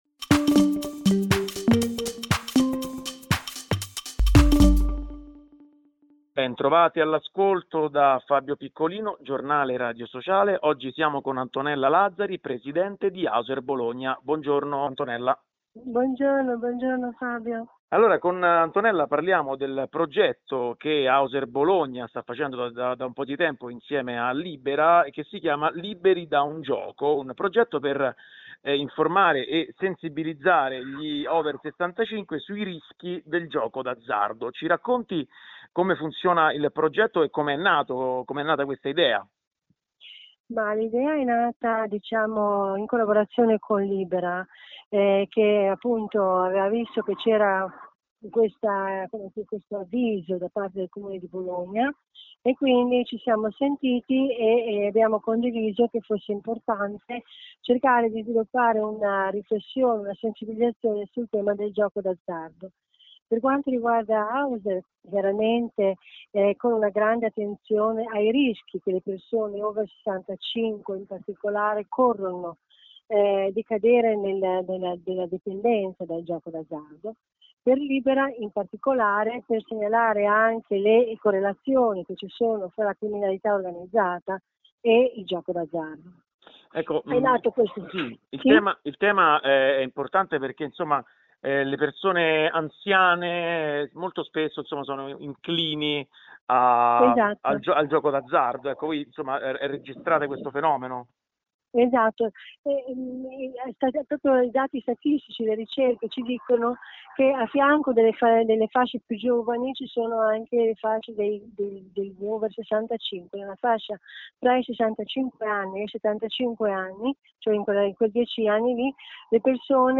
intervista